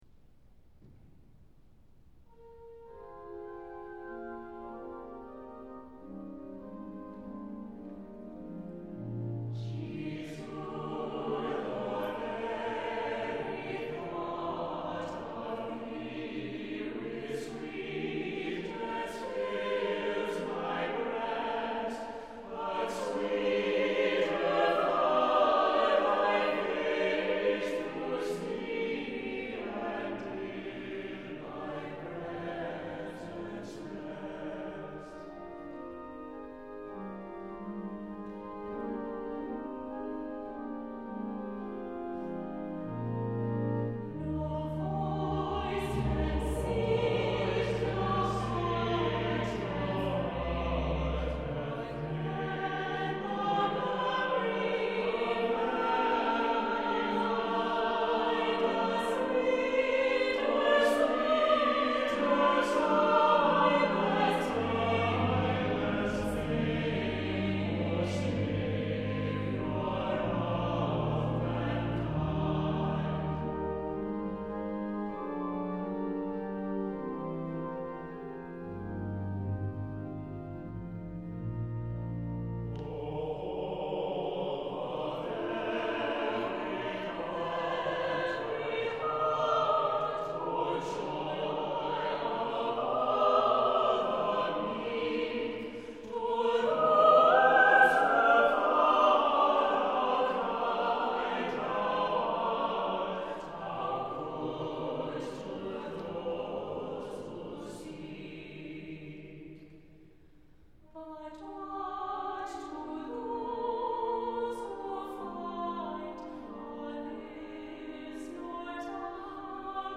Lyrical version of this well-known text